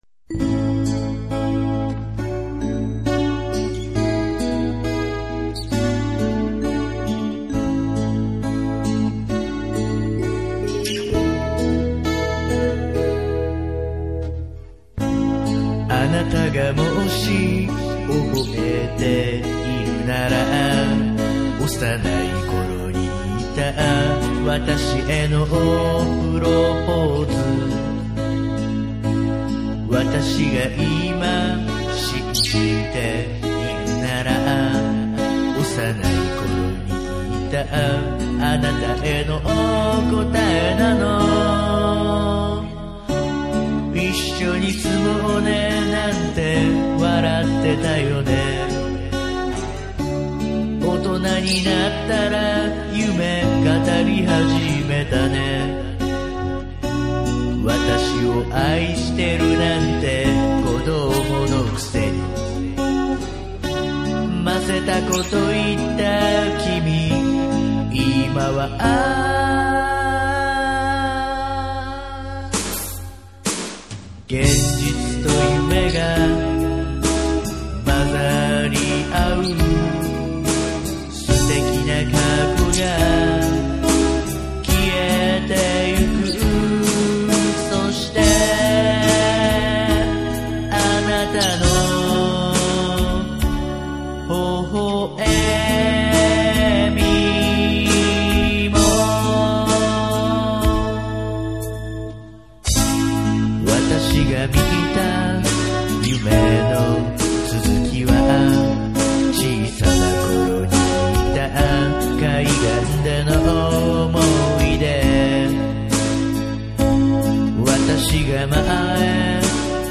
曲的には、作詞者からゆっくりとした曲とあったので、スローテンポでメロディアスに。 ギターでシンプルに決めてみました。
編曲に関しては、シンプルで優しく穏やかな曲に仕上げました。
録音は、しっかりとした音質成分、それから優しさと暖かさを出すよう、 がんばってみました。
歌は下手なので、どうしようもありません。